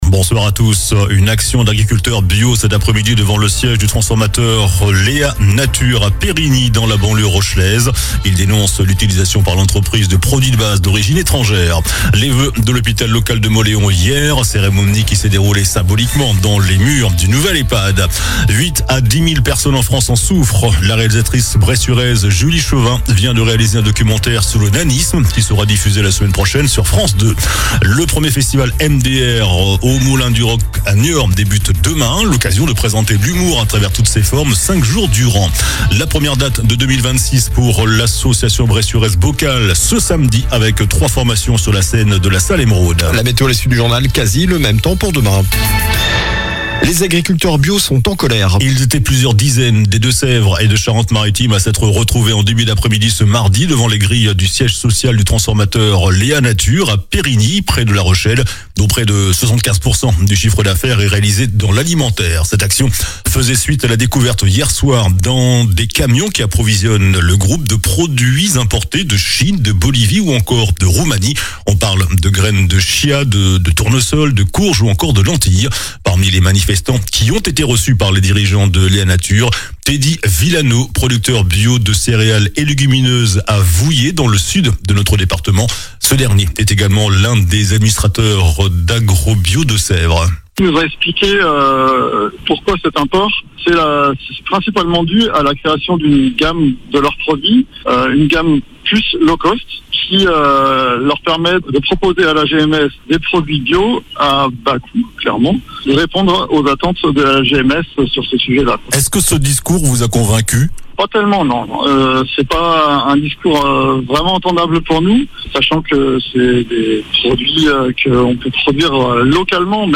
Journal du mardi 13 janvier (soir)